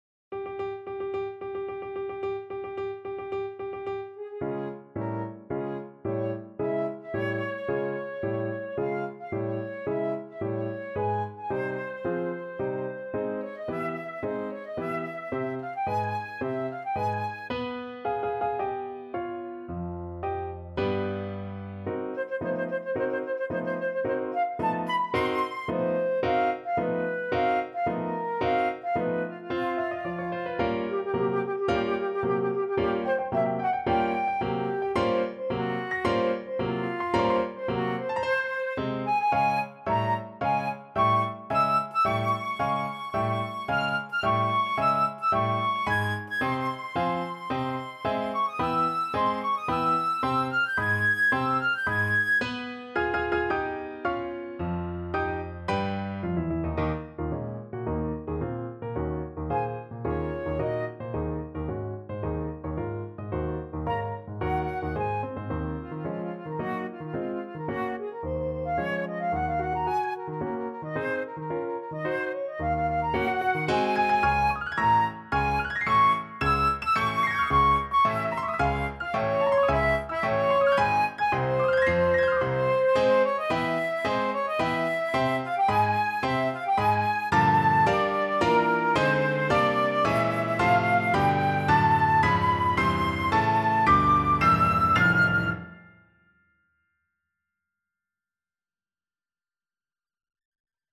Flute version
4/4 (View more 4/4 Music)
~ = 110 Tempo di Marcia
Classical (View more Classical Flute Music)